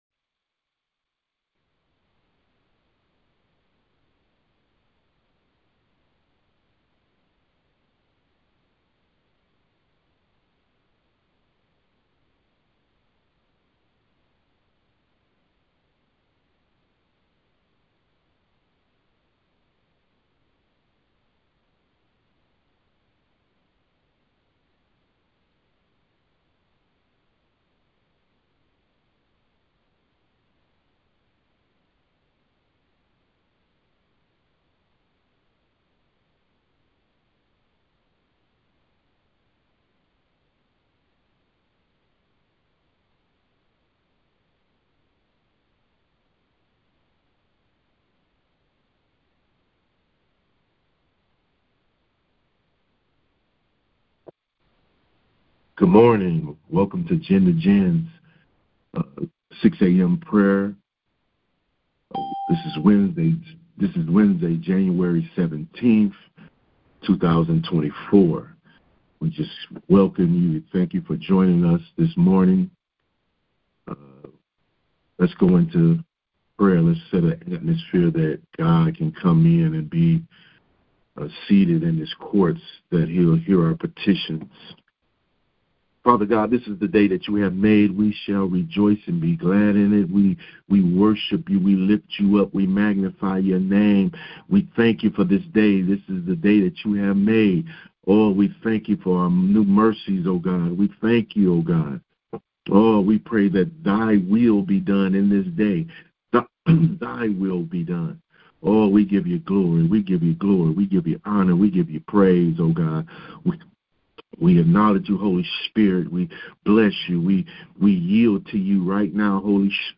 Prayers for Men taken from the weekly prayer conference line.